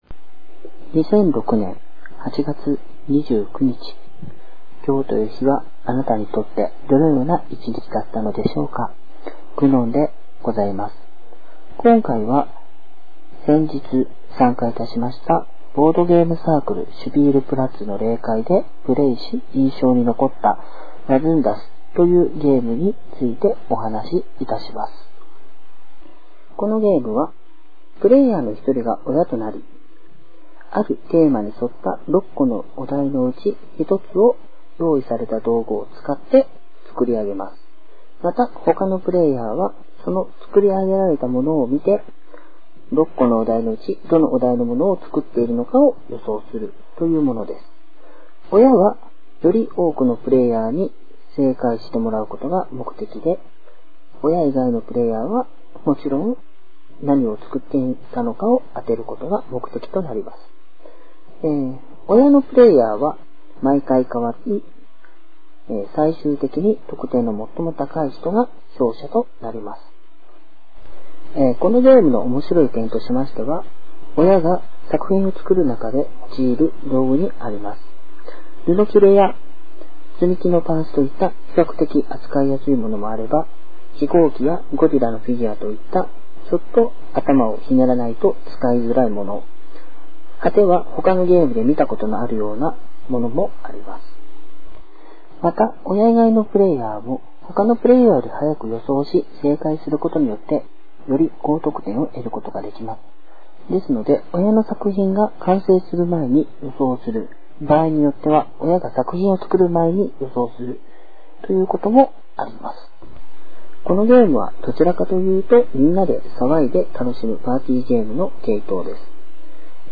以前よりは多少聞きやすくなっているとは思います。 第６回：インスピレーション創作 このワズンダスをプレイしていて、実際に「親が悩んでいるうちに予想し、正解する」という事態も起こりました。